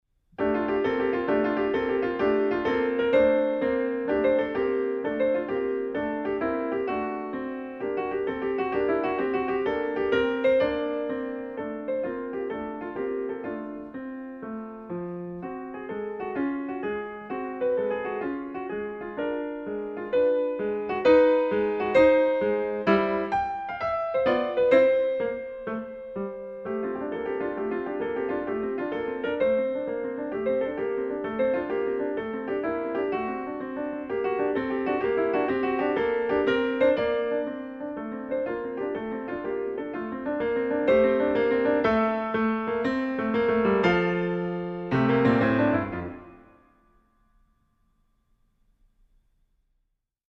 Australian piano music